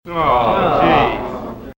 S-AWW-GROUP-C.mp3